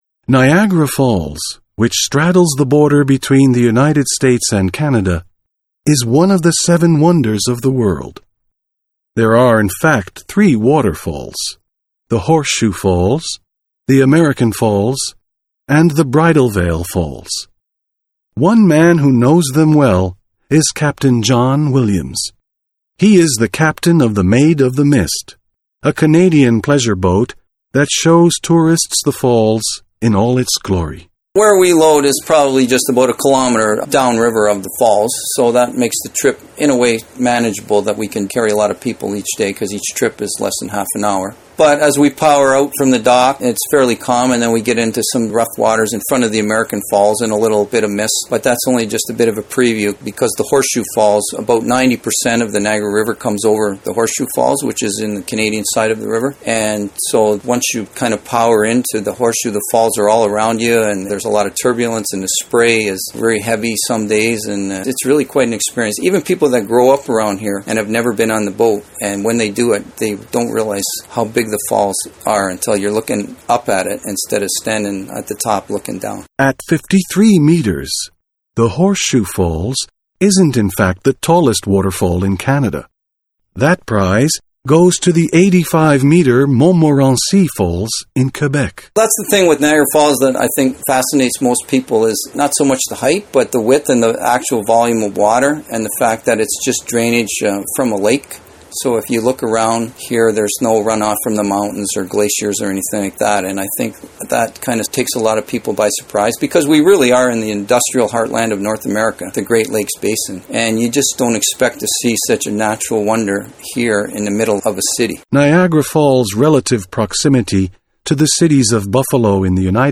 Interview: Wet wet wet